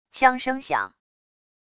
枪声响wav音频